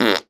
pgs/Assets/Audio/Comedy_Cartoon/fart_squirt_09.wav
fart_squirt_09.wav